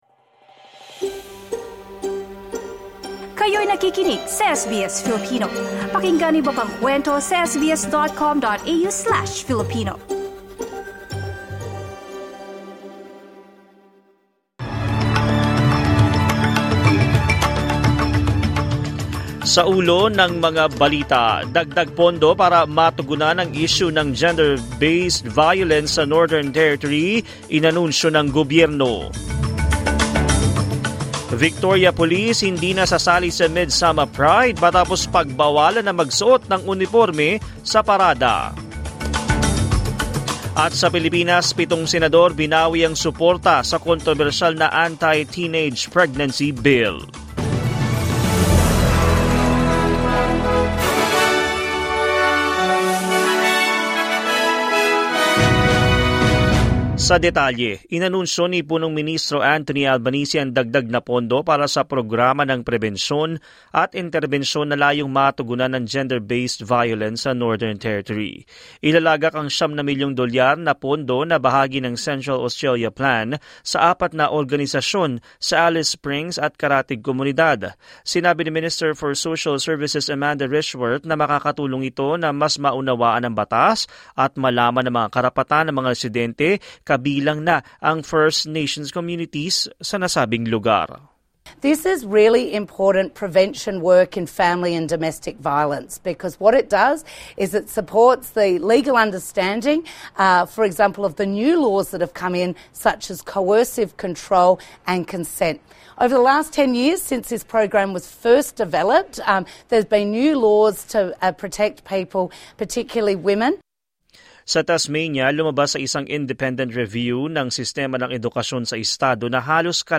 SBS News in Filipino, Thursday 23 January 2025